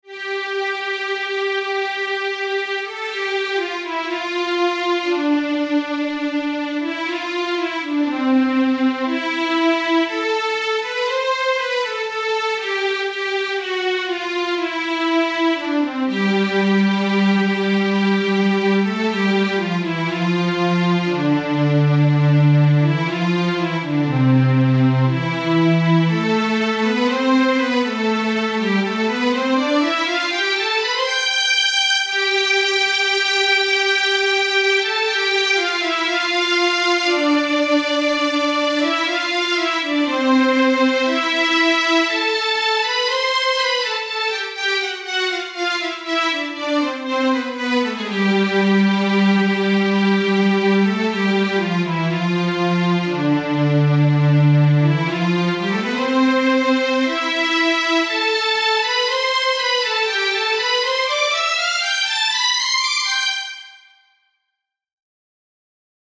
Warmstring.mp3